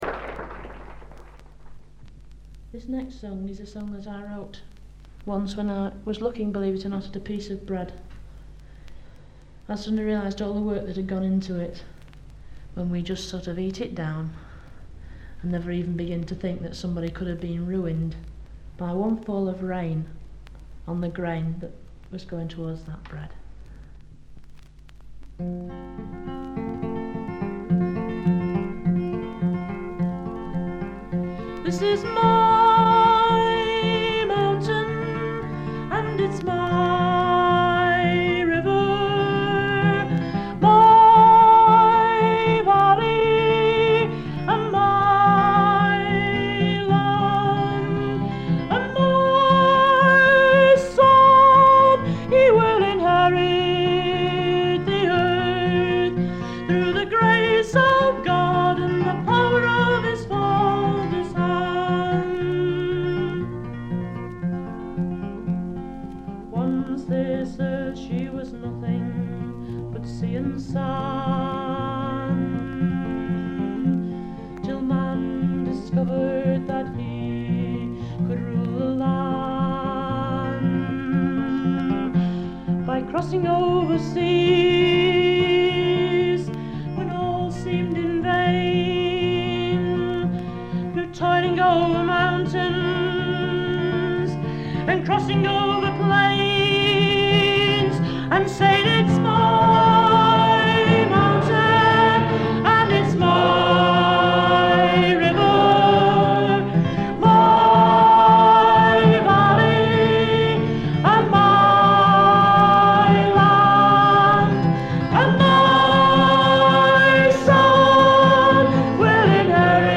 軽微なバックグラウンドノイズにチリプチ少々。
内容はギター弾き語りのライブで、全11曲のうち自作3曲、カヴァー1曲、残りがトラッドという構成です。
試聴曲は現品からの取り込み音源です。